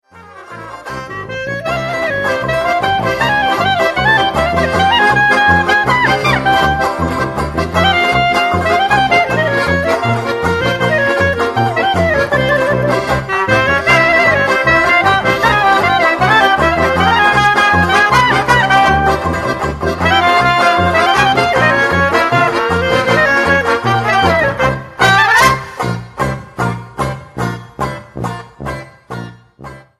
59 Minuten und 33 Sekunden Tanzmusik & Hörvergnügen